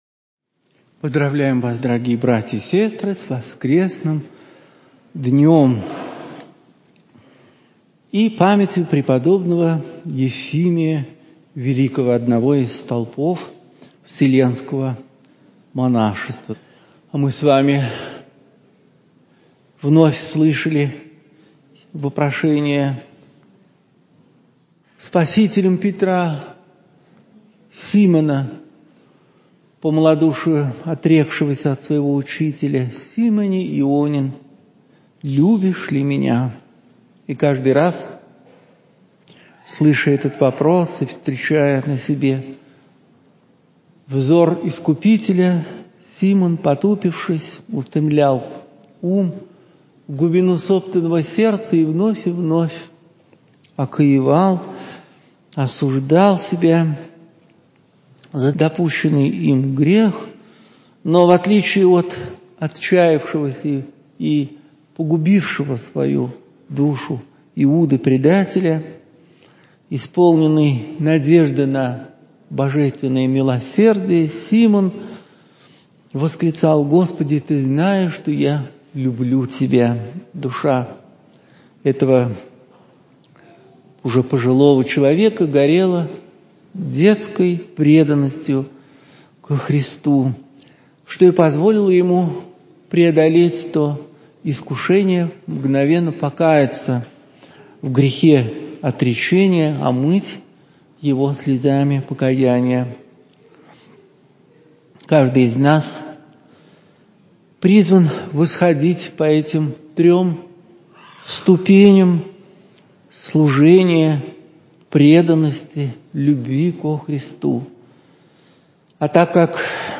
Проповедь в храме Всех Святых Алексеевского монастыря, 1 февраля 2020, на утрене.